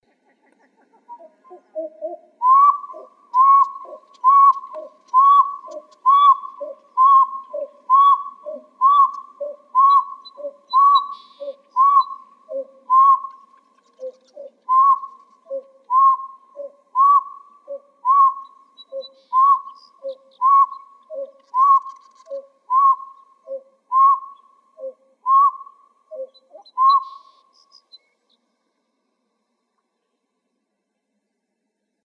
piedbilledgrieb.wav